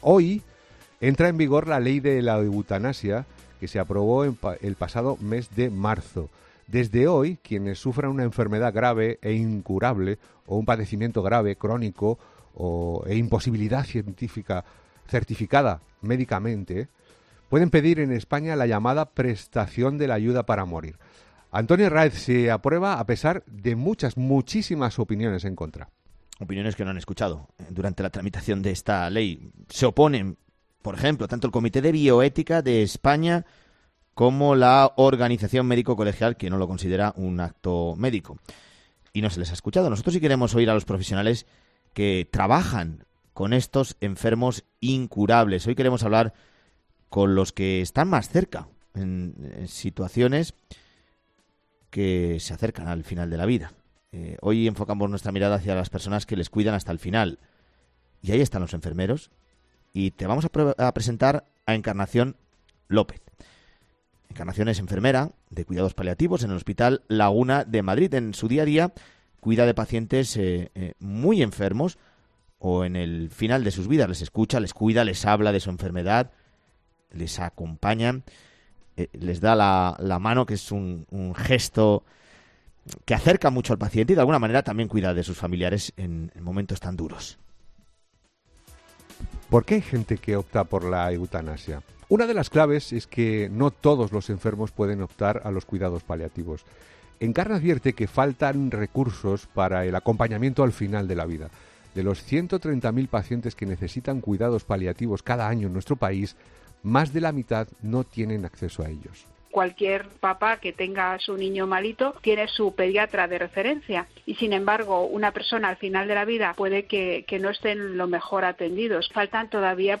En ‘Herrera en COPE’ hablamos con los profesionales que trabajan con estos enfermos incurables, con los que están más cerca en situaciones que se acercan al final de la vida.